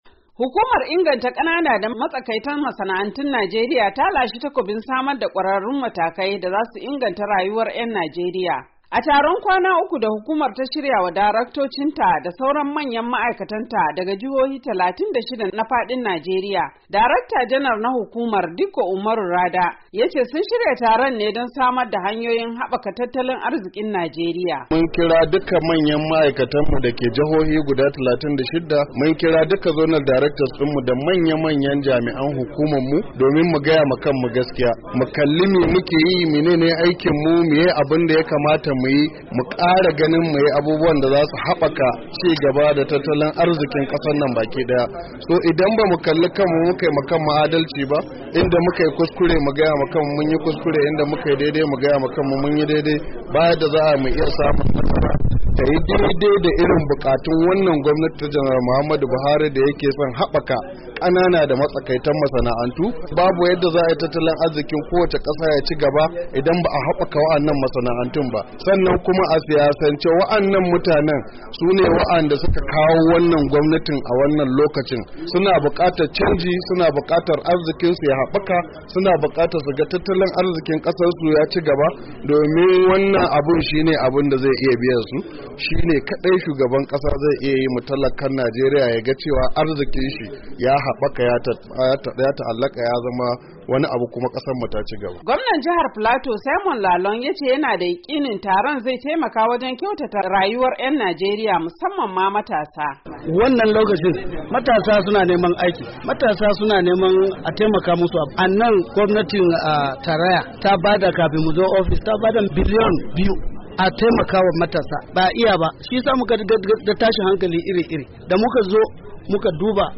Rahoton taraon bunkasa masana'antun Najeriya-2:52"